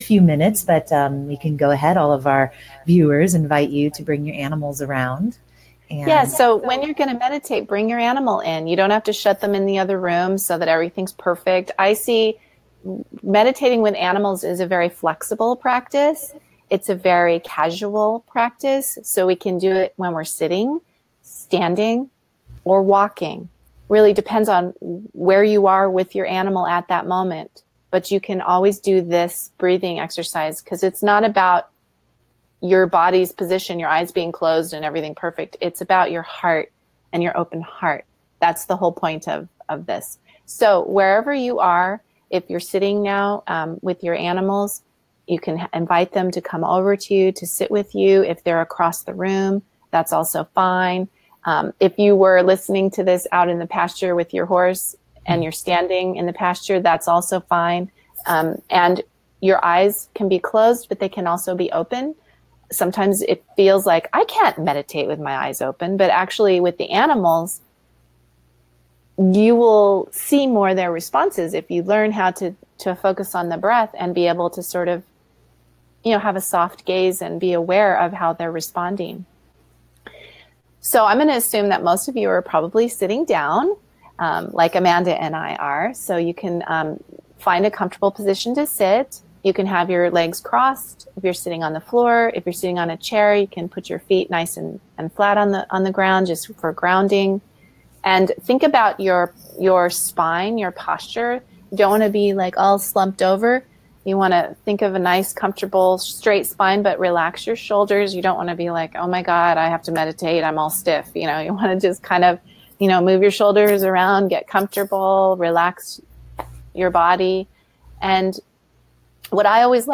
Meditation mp3
Meditation.mp3